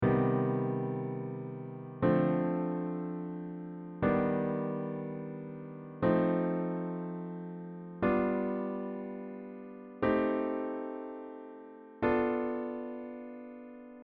C-Mm-Chords
C-Mm-Chords.mp3